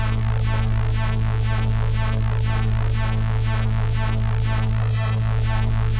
Index of /musicradar/dystopian-drone-samples/Tempo Loops/120bpm
DD_TempoDroneD_120-A.wav